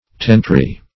Search Result for " tentory" : The Collaborative International Dictionary of English v.0.48: Tentory \Tent"o*ry\, n. [L. tentorium a tent.]